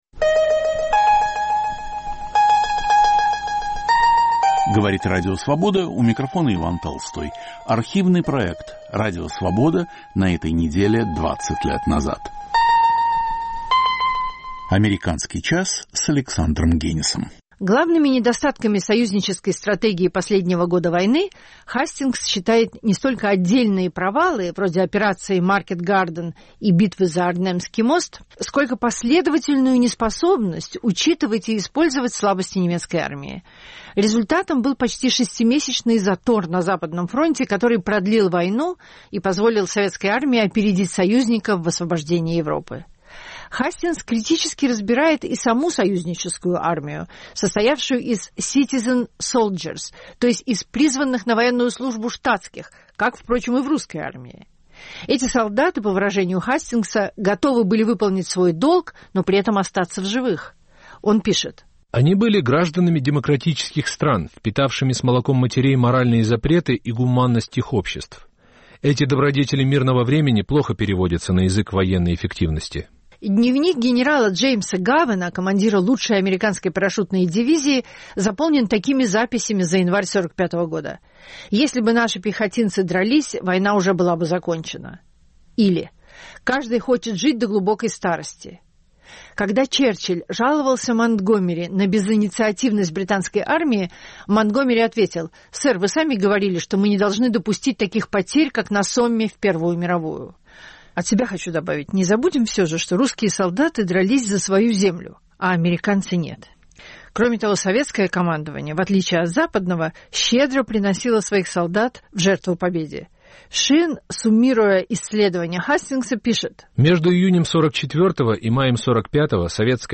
Автор и ведущий Александр Генис.